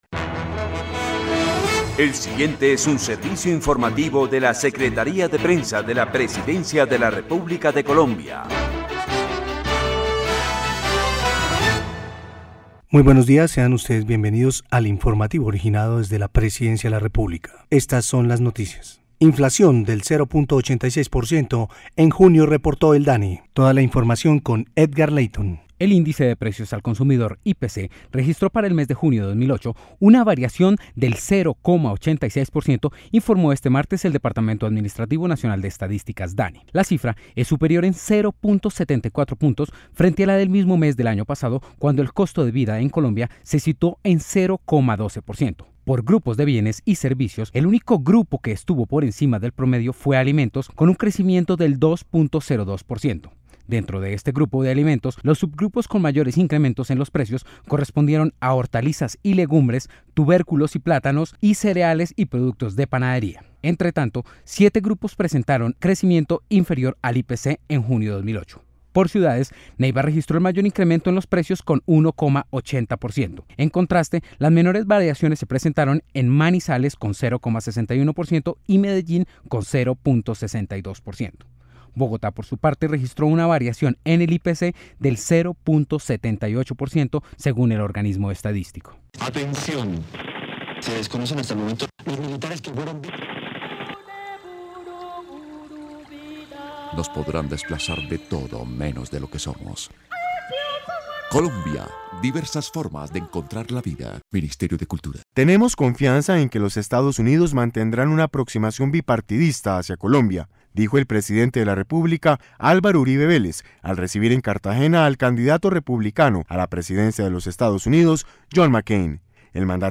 La Secretaría de Prensa de la Presidencia presenta a sus usuarios un nuevo servicio: El Boletín de Noticias, que se emite de lunes a viernes, cada tres horas, por la Radio Nacional de Colombia, en las frecuencias 570 AM y 95.9 FM.